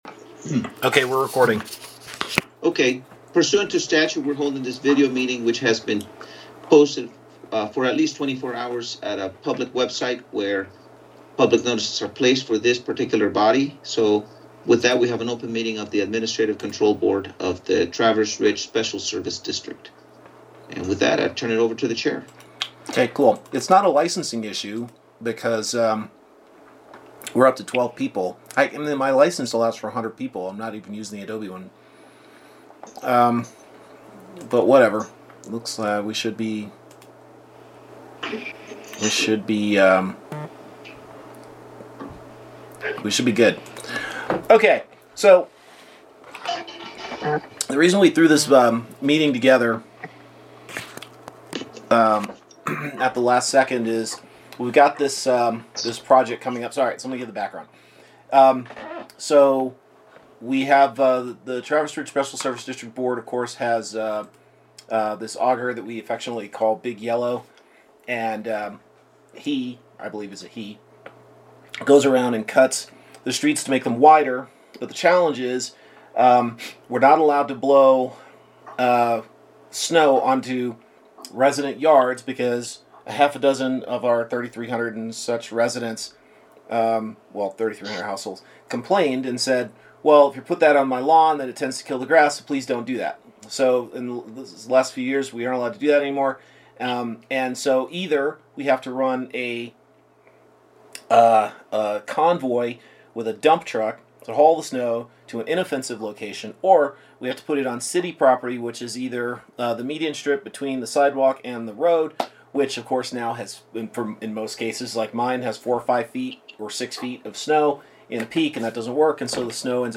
Meeting recording